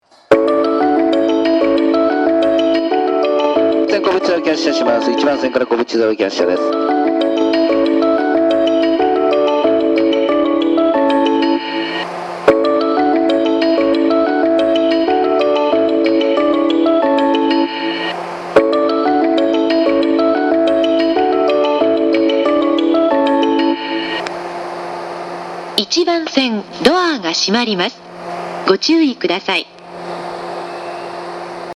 発車メロディー
4コーラスです。